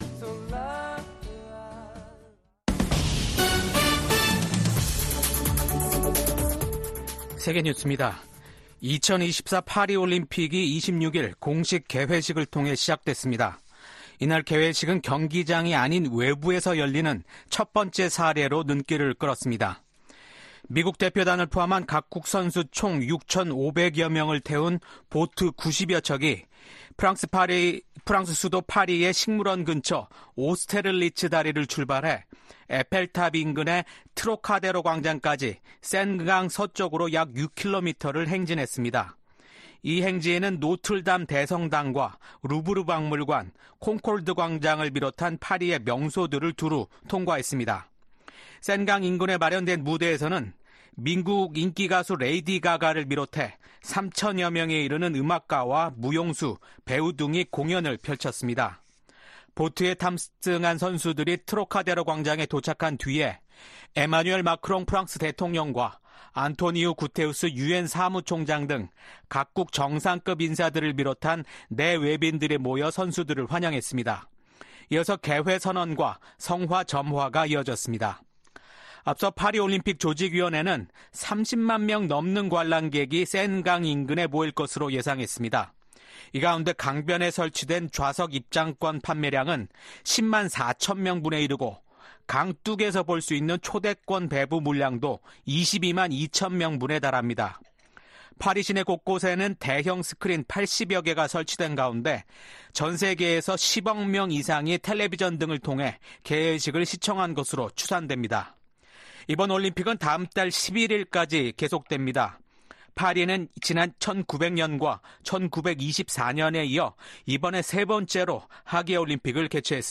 VOA 한국어 아침 뉴스 프로그램 '워싱턴 뉴스 광장' 2024년 7월 27일 방송입니다.